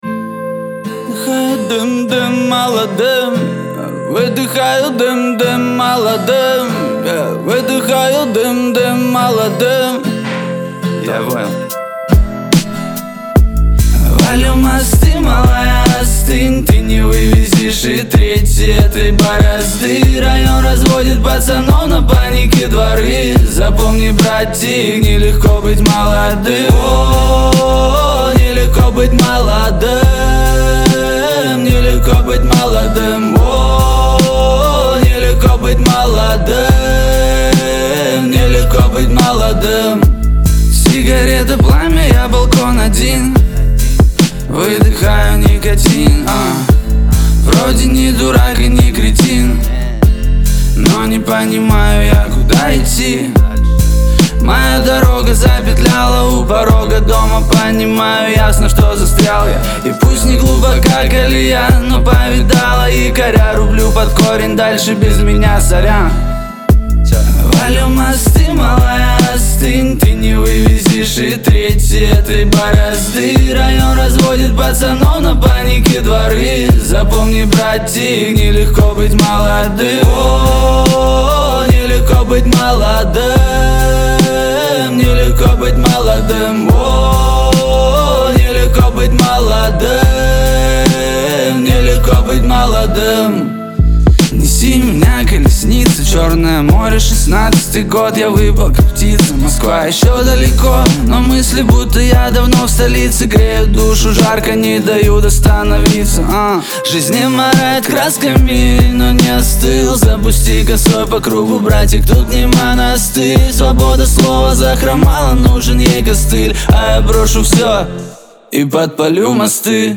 это яркий трек в жанре хип-хоп